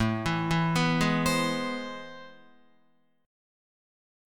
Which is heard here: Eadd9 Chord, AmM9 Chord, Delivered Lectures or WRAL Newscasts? AmM9 Chord